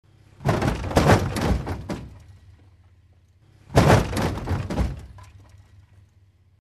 1. Звук удара в машине при аварии изнутри салона n2. Авария автомобиля звук удара внутри машины